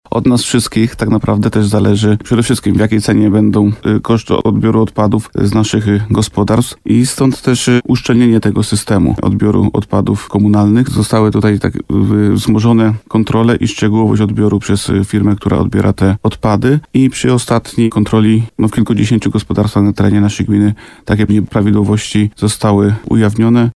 Wójt gminy Łukowica Bogdan Łuczkowski apeluje do mieszkańców, żeby tego nie robić.